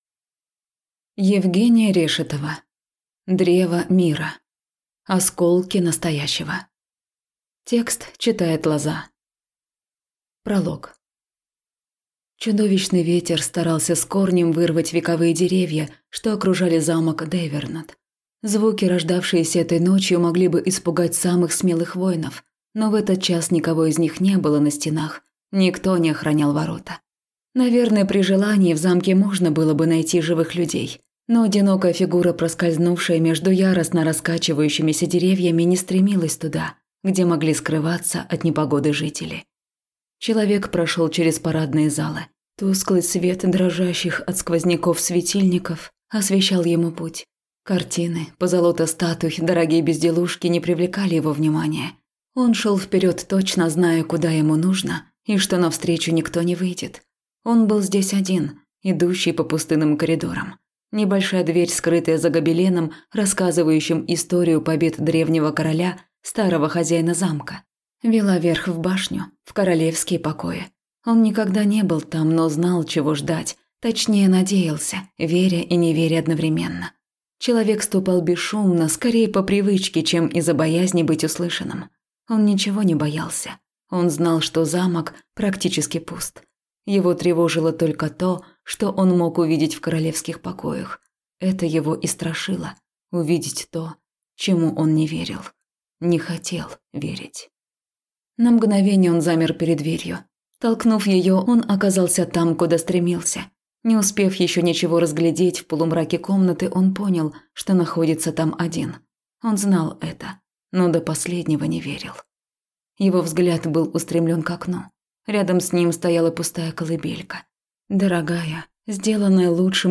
Аудиокнига Древо мира. Осколки настоящего | Библиотека аудиокниг